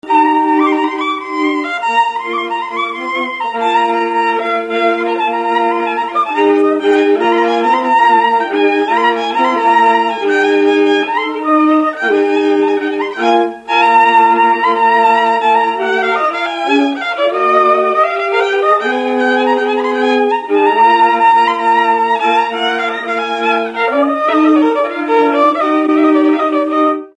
Dallampélda: Hangszeres felvétel
Erdély - Udvarhely vm. - Gagy
hegedű
kontrahegedű
Műfaj: Lassú csárdás
Stílus: 4. Sirató stílusú dallamok